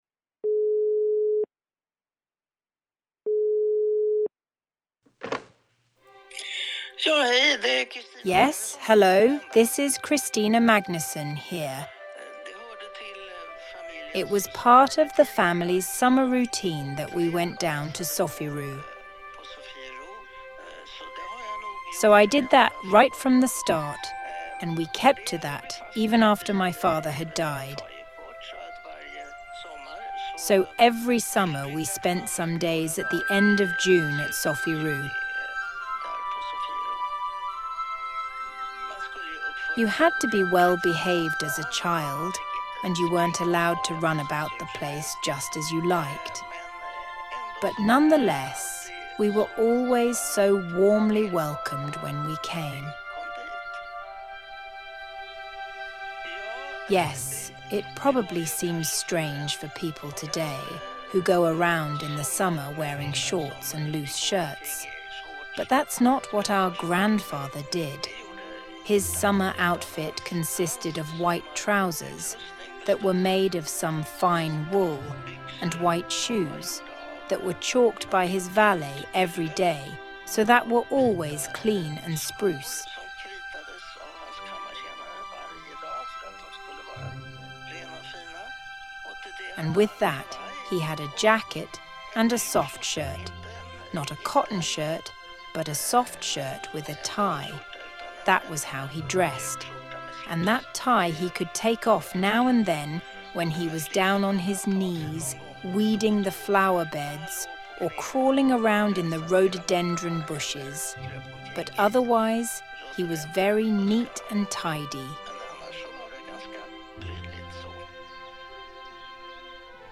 Listen to Princess Christina's memories from Sofiero.